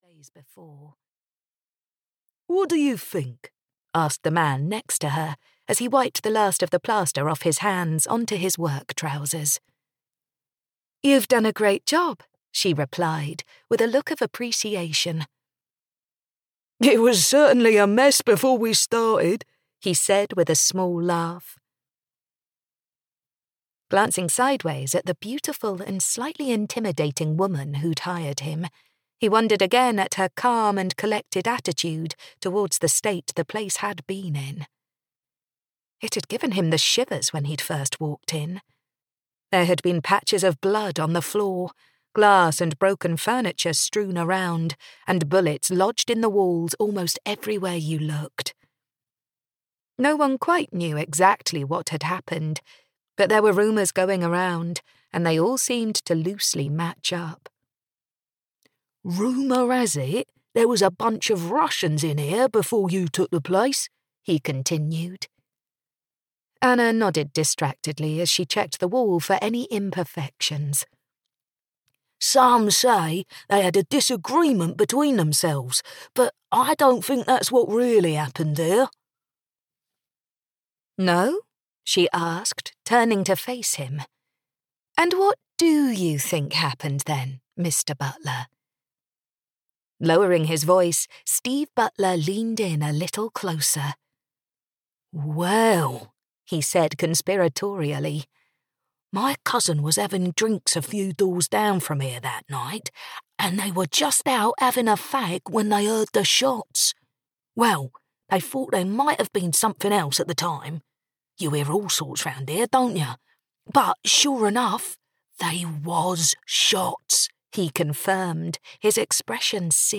Ruthless Girl (EN) audiokniha
Ukázka z knihy